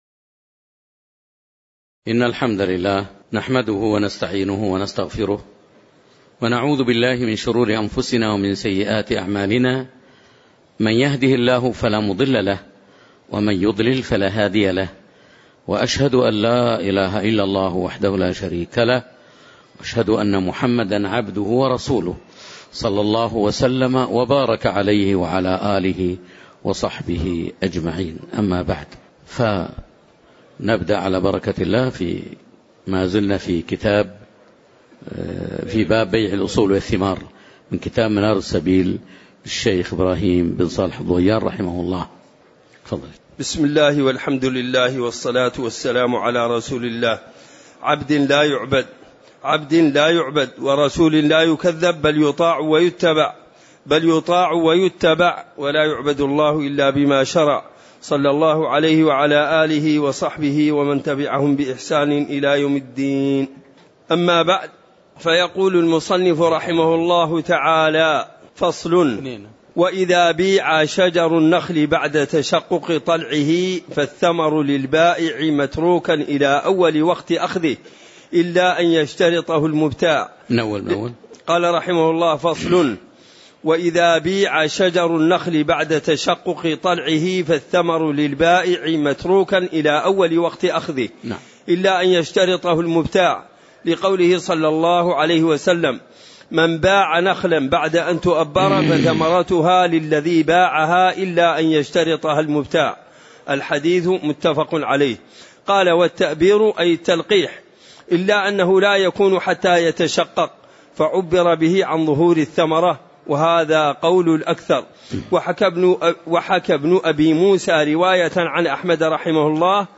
تاريخ النشر ١ ربيع الأول ١٤٤٠ هـ المكان: المسجد النبوي الشيخ